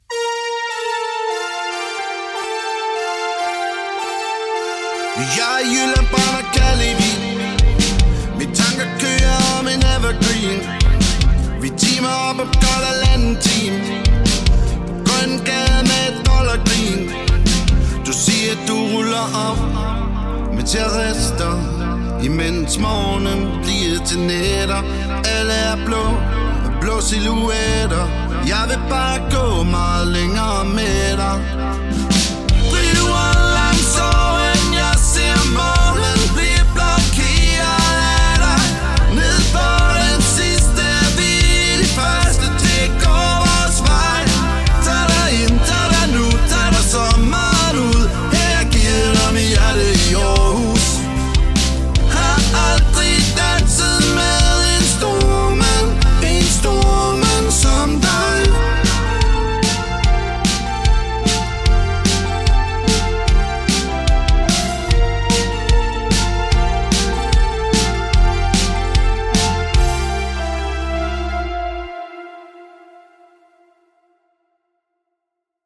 LIVE Koncert
• Coverband